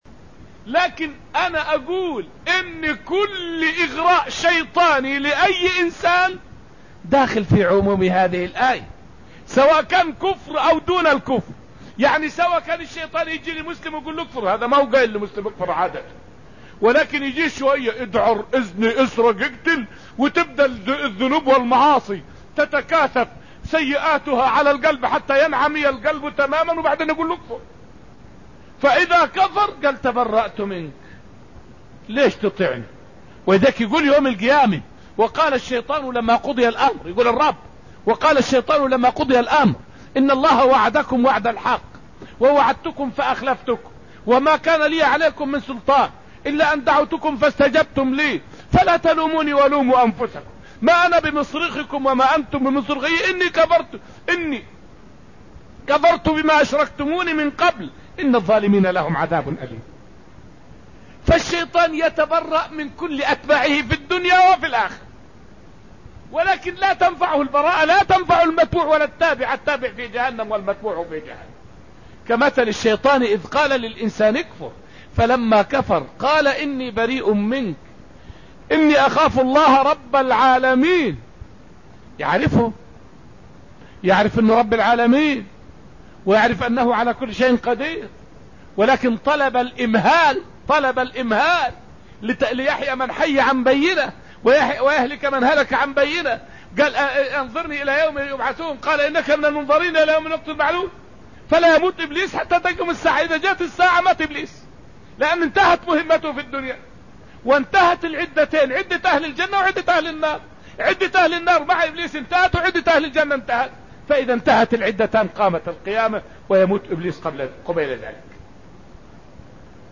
فائدة من الدرس العاشر من دروس تفسير سورة الحشر والتي ألقيت في المسجد النبوي الشريف حول خطبة الشيطان في أهل النار.